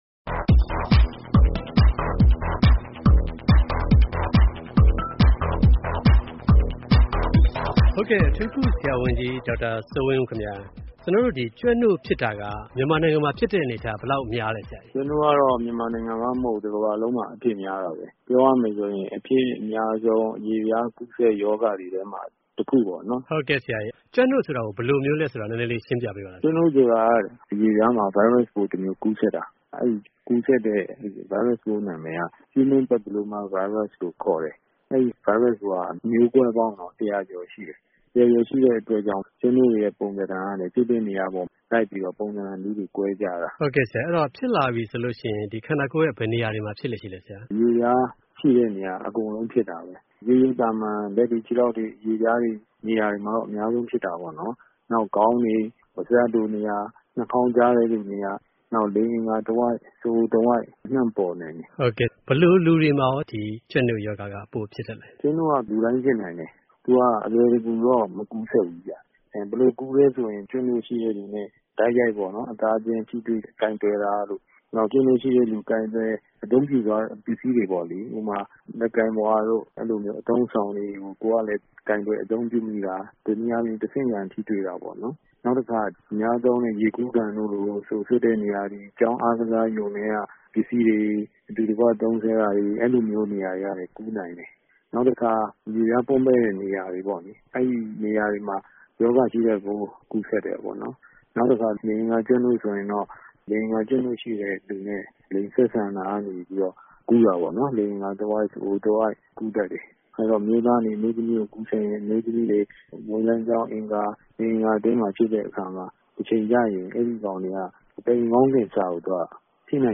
ဆက်သွယ်မေးမြန်း တင်ပြထားပါတယ်။